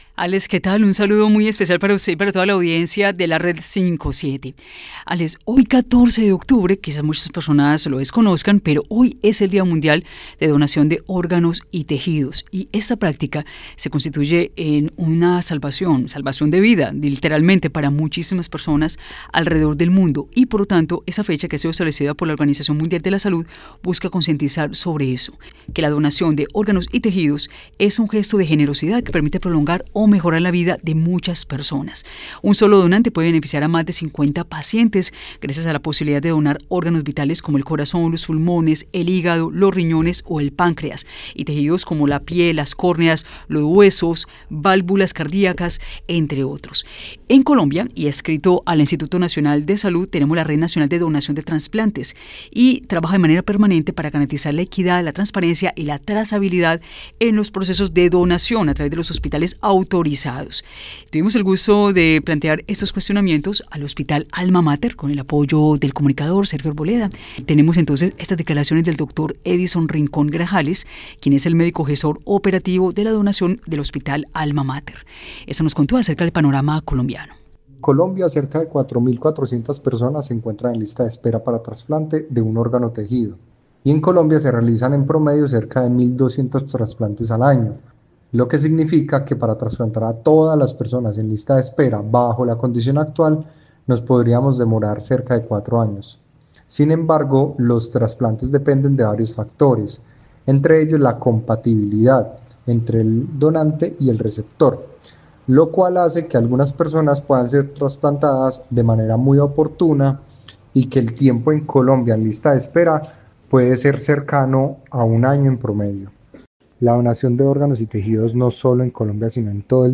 Escucha la entrevista de la emisora de la UdeA a nuestro Médico Gestor Operativo de la Donación